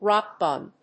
アクセントróck càke [bùn]